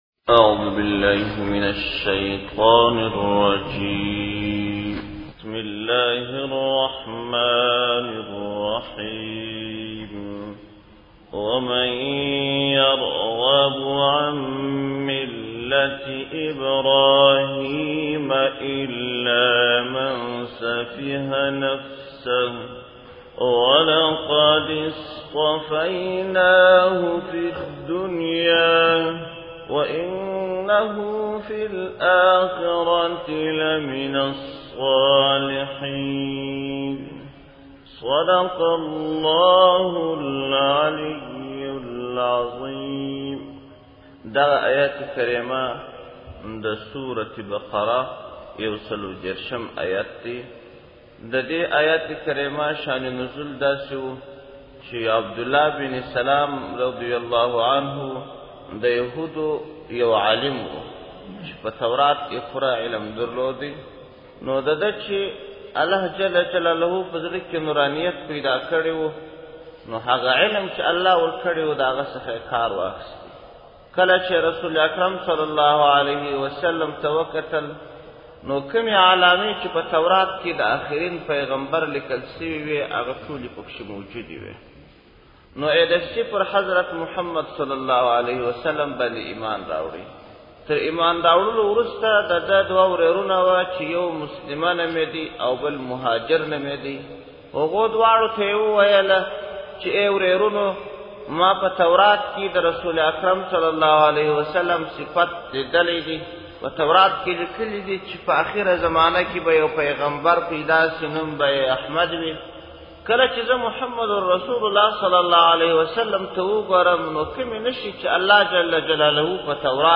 جولای 20, 2016 تفسیرشریف, ږغیز تفسیر شریف 1,129 لیدنی